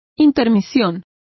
Complete with pronunciation of the translation of intermission.